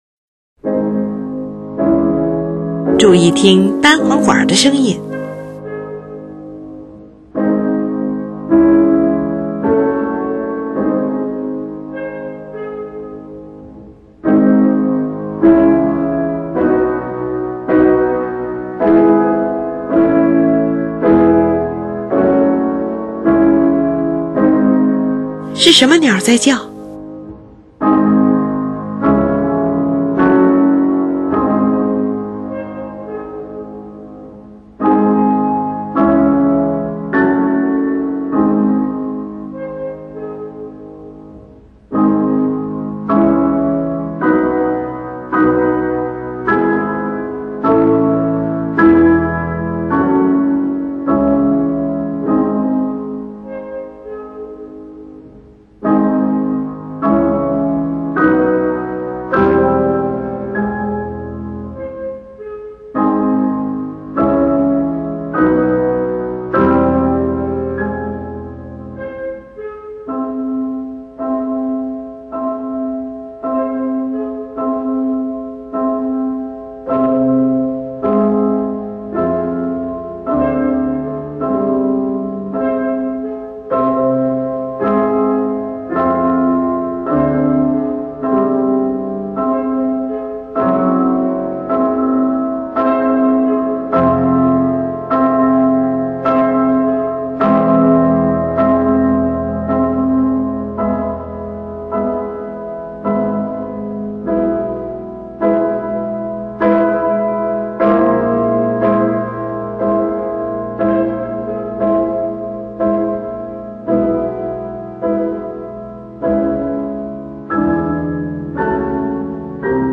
注意听单簧管的声音！
本曲用两种乐器各表现一种音乐形象。两架钢琴显得恬静安详，呈现出晨曦中山林的景象。单簧管惟妙惟肖地模仿出杜鹃的啼叫。